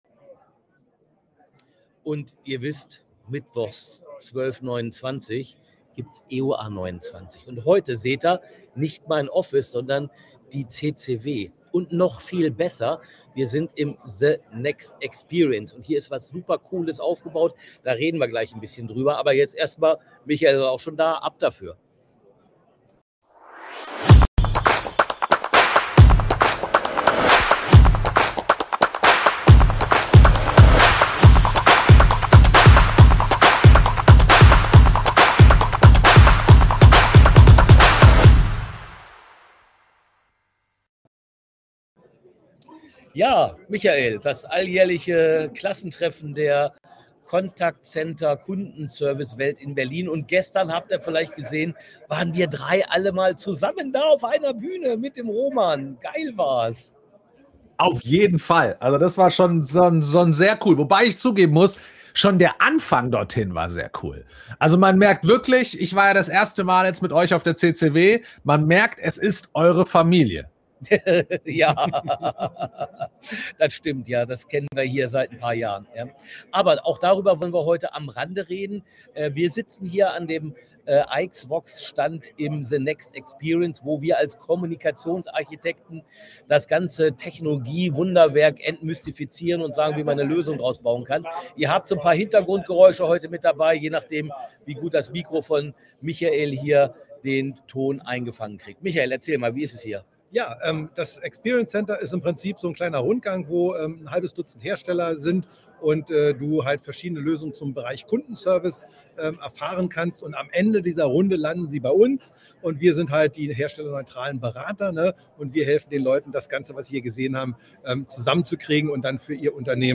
EOA29 - Live von der CCW 2026 ~ EOA29 Podcast